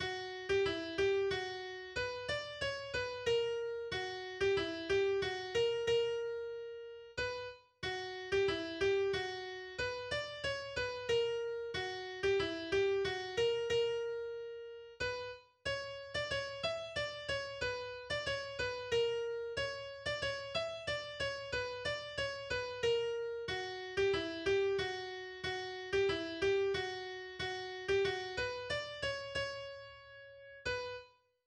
Gesungen wird es auf eine norwegische Volksweise